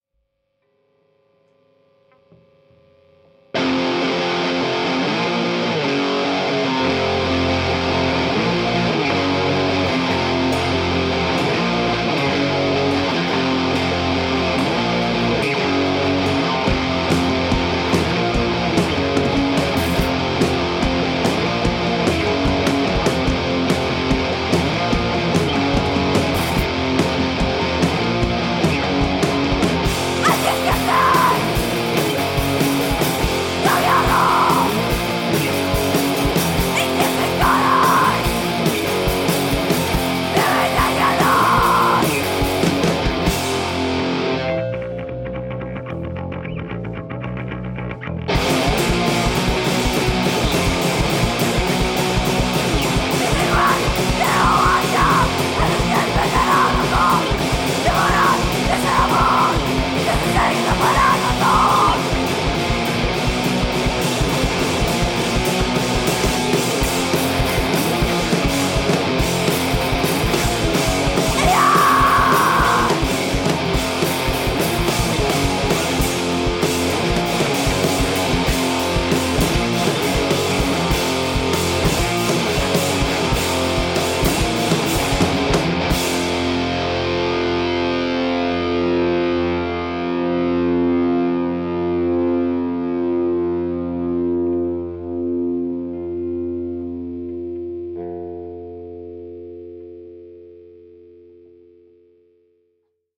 DIY Hardcore Punk band from Ioannina, Greece
κιθάρα-φωνητικά
drums-φωνητικά